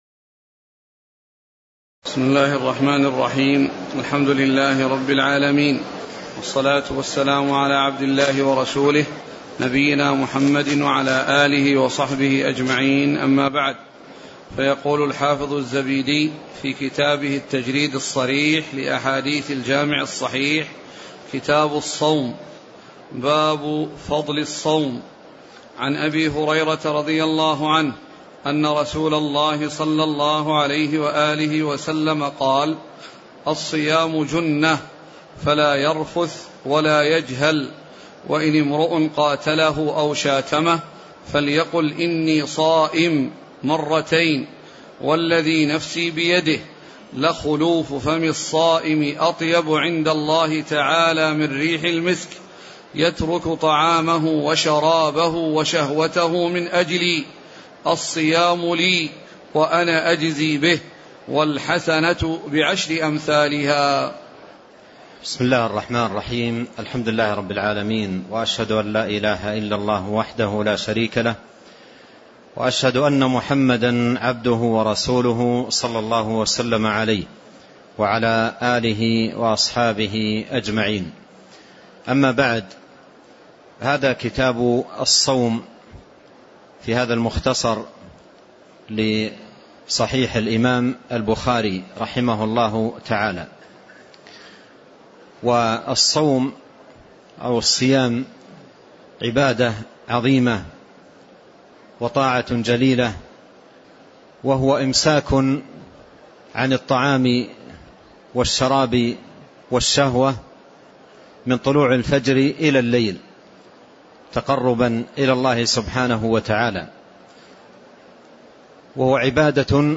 تاريخ النشر ٤ رمضان ١٤٣٤ هـ المكان: المسجد النبوي الشيخ